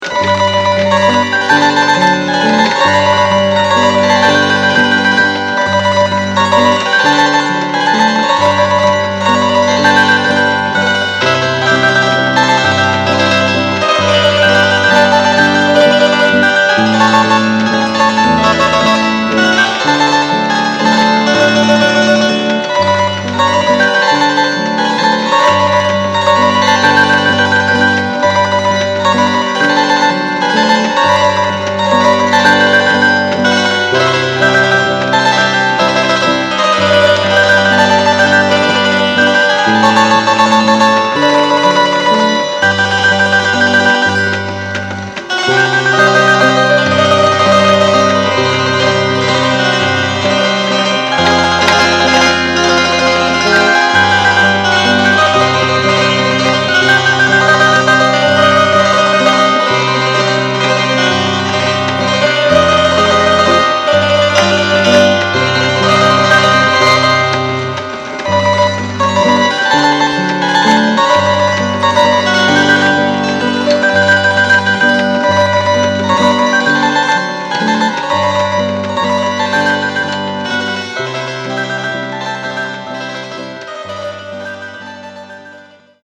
Triola
Here it is played on a Triola mandoline-zither.